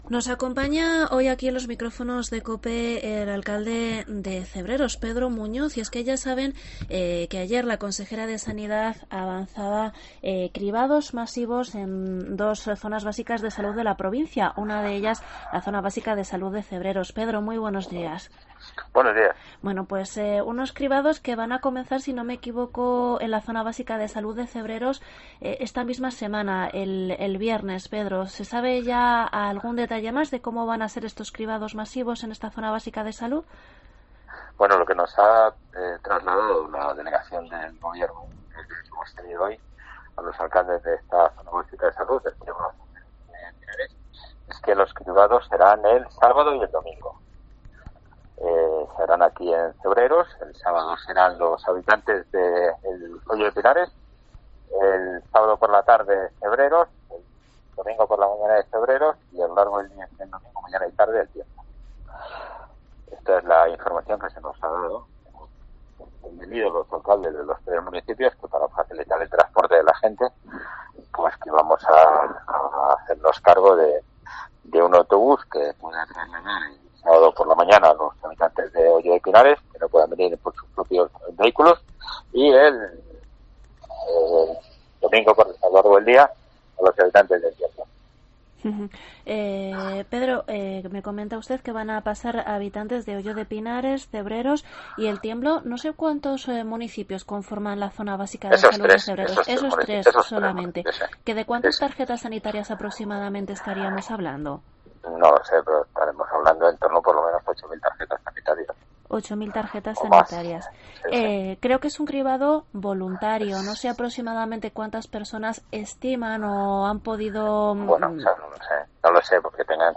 Entrevista alcalde de Cebreros